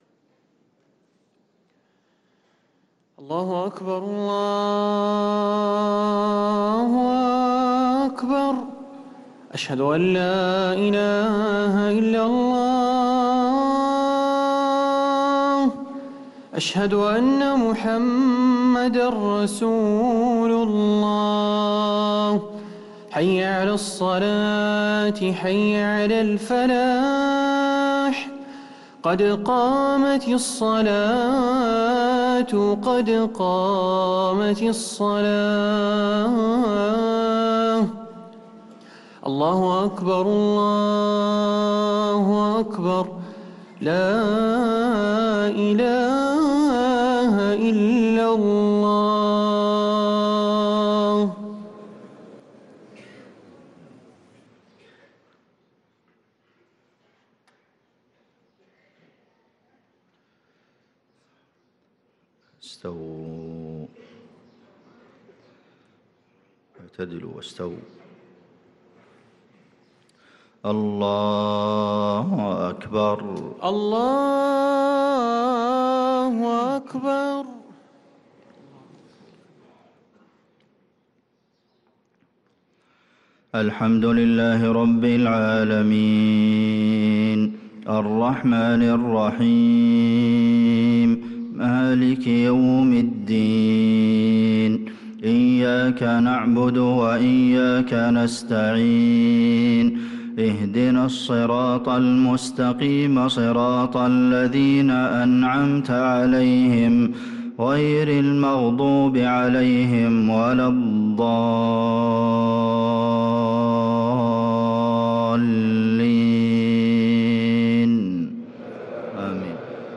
صلاة الفجر للقارئ عبدالمحسن القاسم 24 رجب 1445 هـ
تِلَاوَات الْحَرَمَيْن .